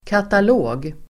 Uttal: [katal'å:g]